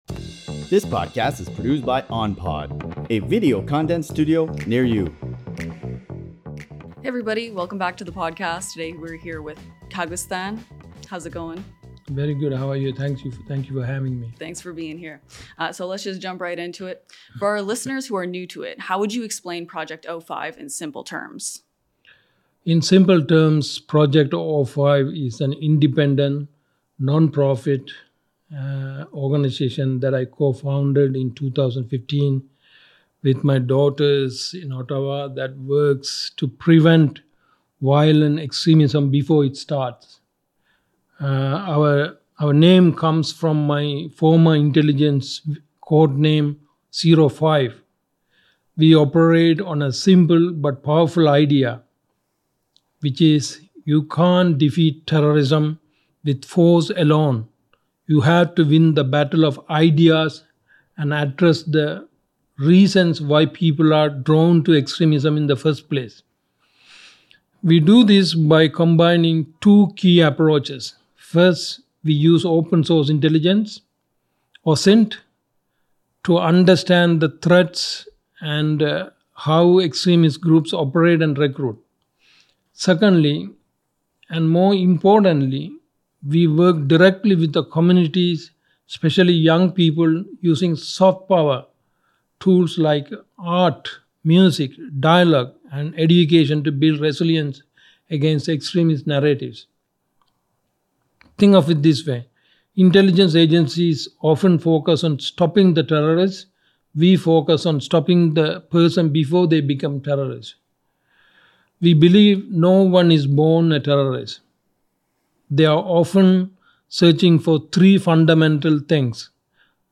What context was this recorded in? Recorded at OnPod Studios – Canada’s fully automated, self-serve podcast studios.